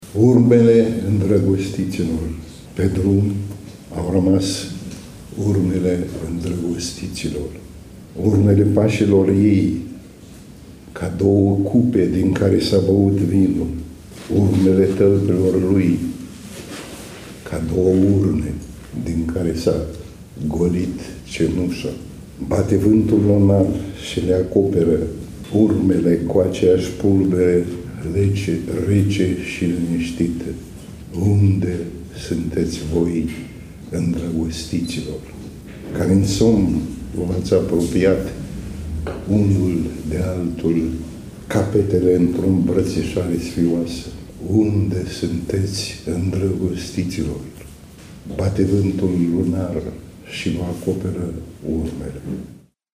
Întâi ne recită scriitorul Nechita Danilov.
7_Nichita-Danilov-recita-Urmele-indragostitilor-52-secunde.mp3